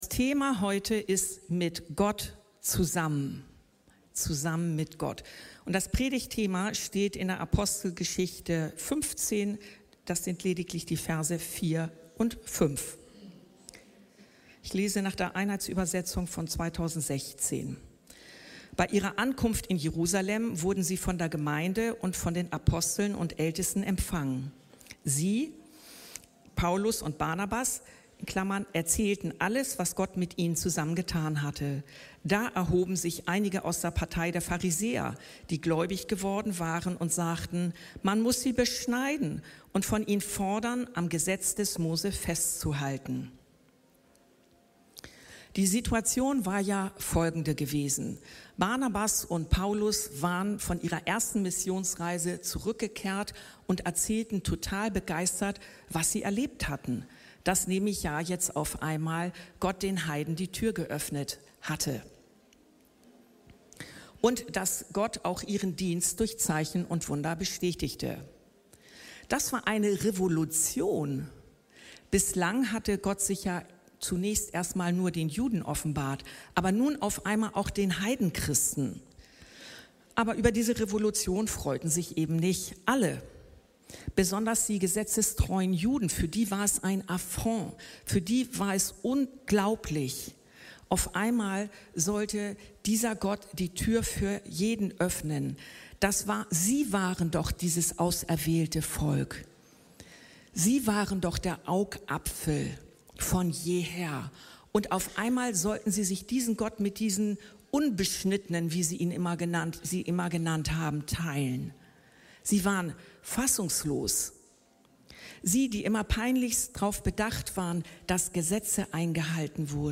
Gott mit uns zusammen ~ Anskar-Kirche Hamburg- Predigten Podcast